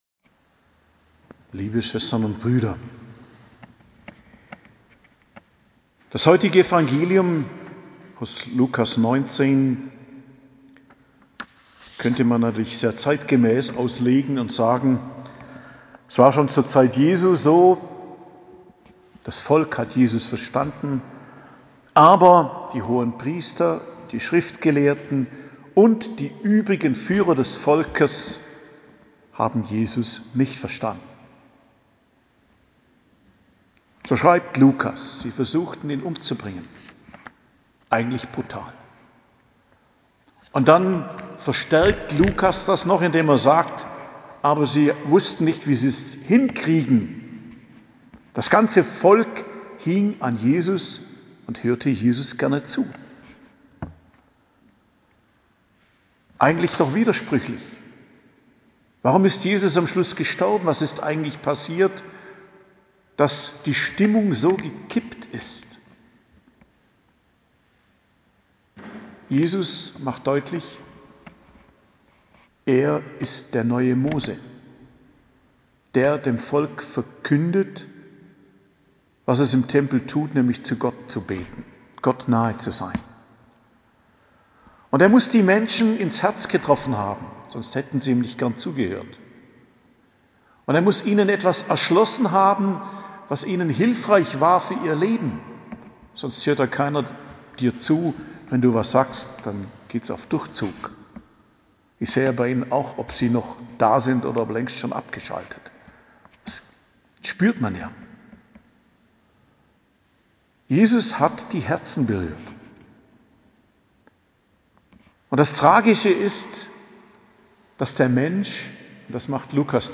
Predigt am Freitag der 33. Woche im Jahreskreis,19.11.2021